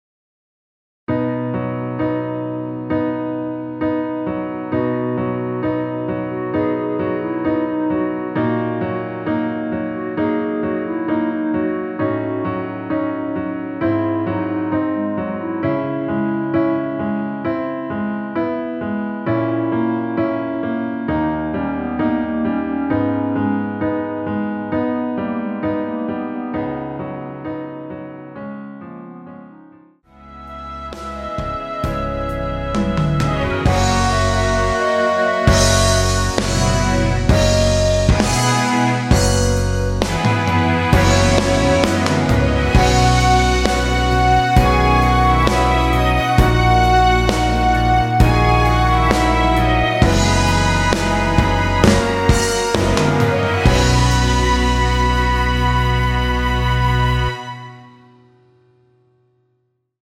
전주 없이 시작하는 곡이라 전주 1마디 만들어 놓았으며
원키에서(-1)내린 멜로디 포함된 MR입니다.(미리듣기 확인)
앞부분30초, 뒷부분30초씩 편집해서 올려 드리고 있습니다.